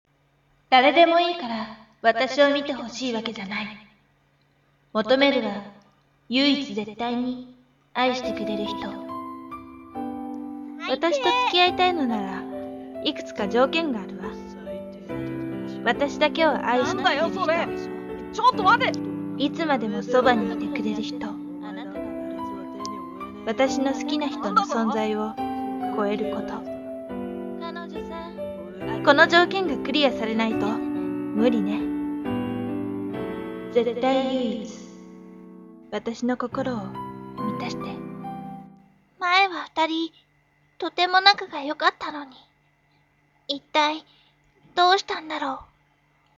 仮想ボイドラＣＭ